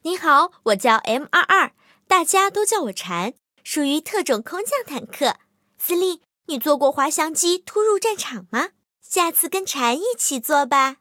M22蝉登场语音.OGG